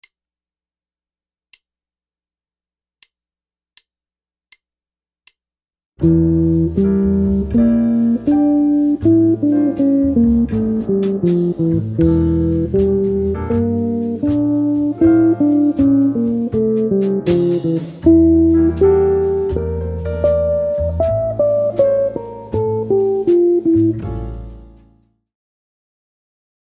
Soloing Lesson 12 - Minor ii-V-I Workout